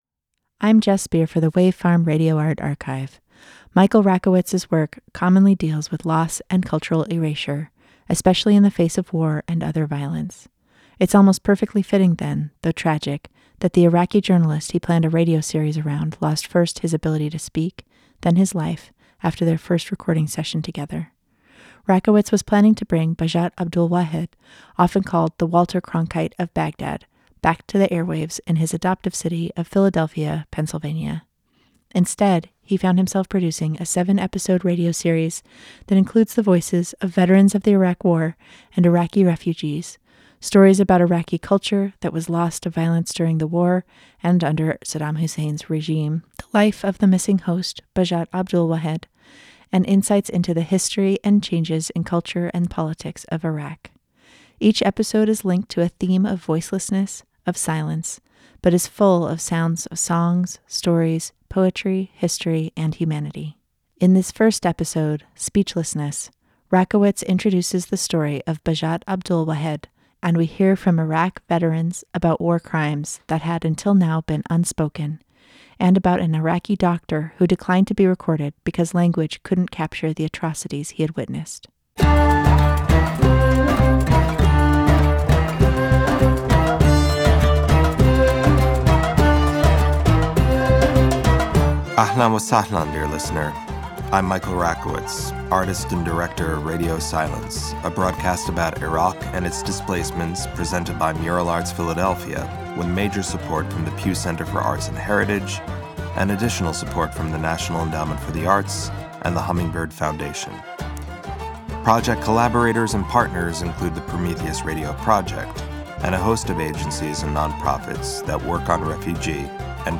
Each episode is linked to a theme of voicelessness, of silence, but is full of sounds of songs, stories, poetry, history, and humanity. As with his other artworks, Rakowitz recruits participants to engage in the art, featuring writing by Iraq war veterans, a radio play by an Iraqi artist, Iraqi music, and interviews with historians, journalists, and everyday people.
The series was produced in collaboration with Mural Arts Philadelphia and originally broadcast on WPPM PhillyCAM Radio 106.5 FM in 2015.